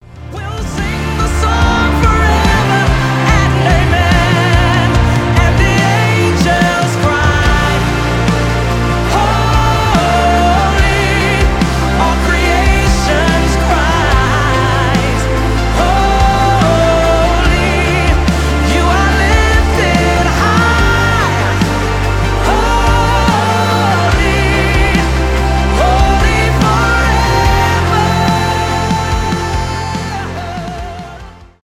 госпел
душевные , христианские